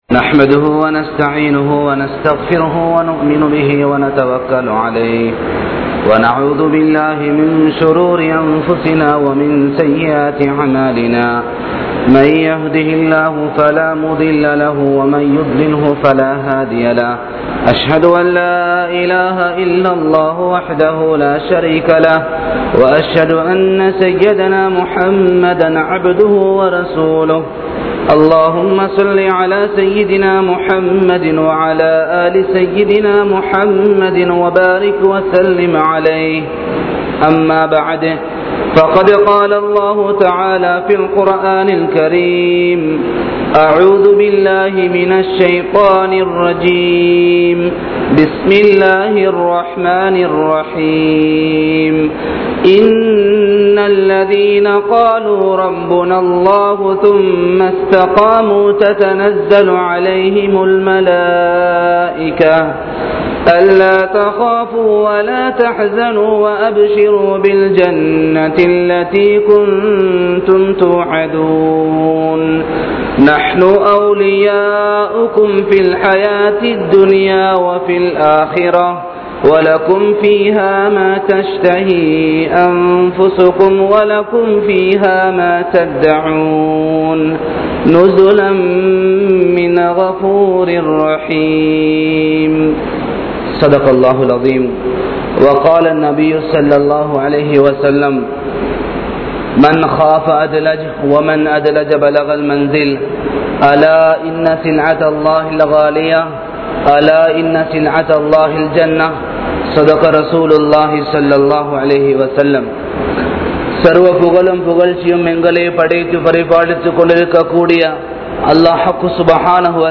Pettroarhalukku Panividai Seiungal (பெற்றோர்களுக்கு பனிவிடை செய்யுங்கள்) | Audio Bayans | All Ceylon Muslim Youth Community | Addalaichenai
Gothatuwa, Jumua Masjidh